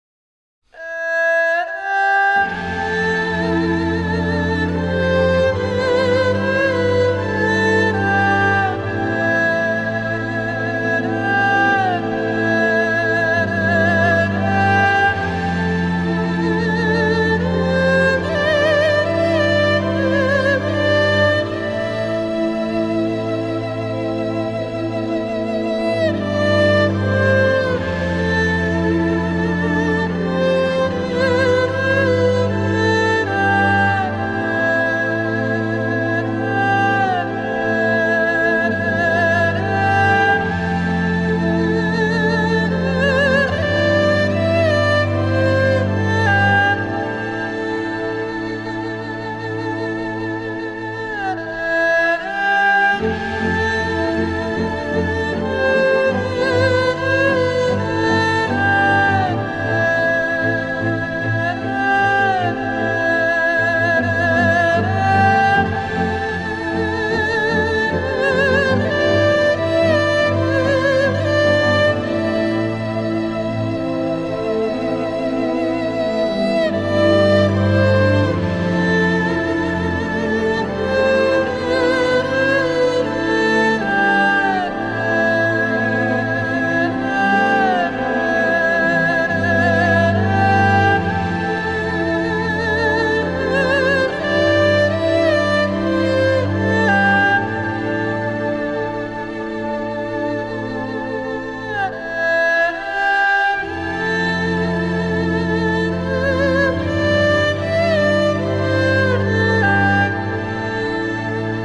★ 融會貫通東西方樂器、傳統與現代的跨時代完美樂章！
★ 輕柔紓緩的美麗樂音，兼具令人震撼感動的發燒音效！